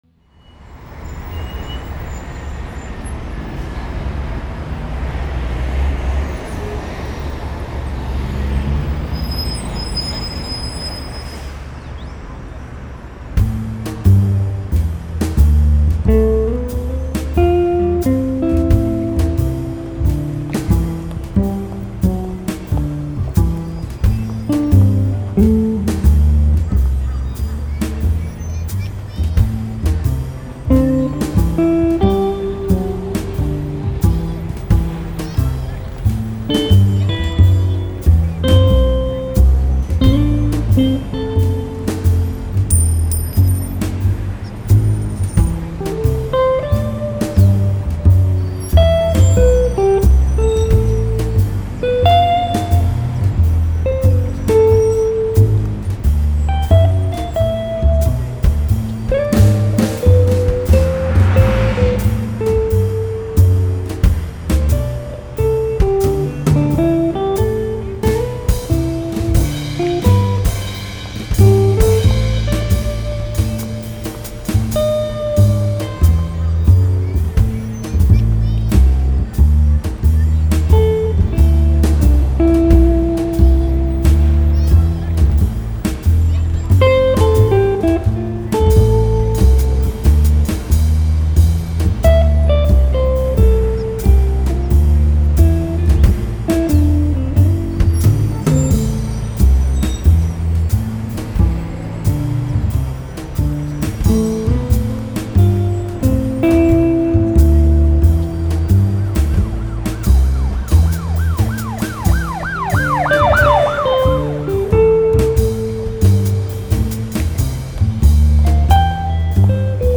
hier also nun mein Take (A-Moll) zum Tonartenvergleich. ;-))
also am ende kommt Beifall - (der Beweis: ich habs bis zu ende gehört).
Dein Jam klingt wie gewohnt tonig und schmeichelt ins Ohr wie Honig.
Backing und gibt dem Track einen relaxten "Lounge"-Charakter.
Schön clean und das Ganze hat seinen eigenen Charakter. Jeder Ton kommt mit eigener, - ich sag mal Message dazu.
P.S. 52 = blues, 53 = Jazz und ich bastel gerade an einem neuen funky-backing in 120 bpm ;-)
Schöner Bar-Sound, wobei das nicht abwertend gemeint ist - ein Caol Ila oder ein Lagavulin im Glas, eine feine Bar mit relaxter Stimmung und so einen Take im Hintergrund..... macht einen perfekten Abend."